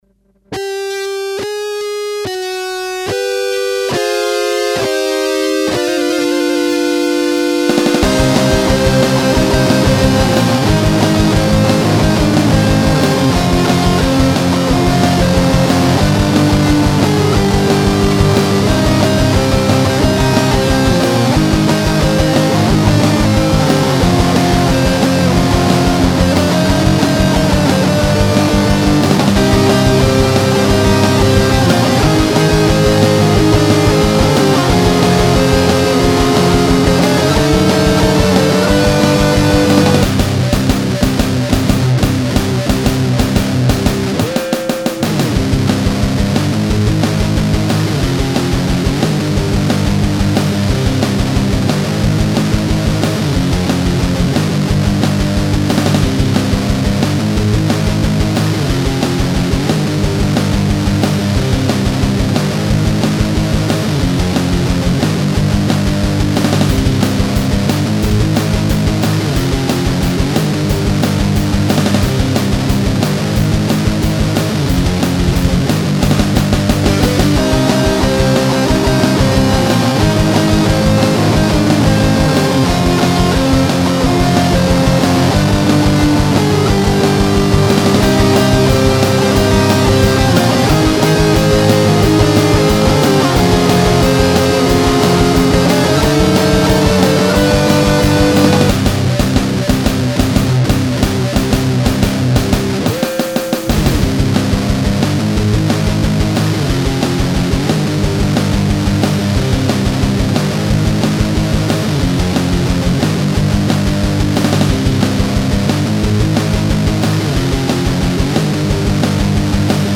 Le tempo est de 180bpm :jap:
merci pour le tempo, oui pas de souci pour l'oreille, ya une version sans ta basse faite à la guitare qq part ?
Edit: Ma guitare a deja bcp de basses a elle toute seule, en fait [:tinostar]
NoBass.mp3